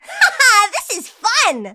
willow_kill_vo_03.ogg